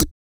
FANCY LIPS09.wav